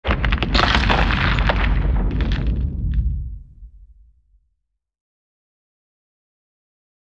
Земля (грунт) — альтернативный вариант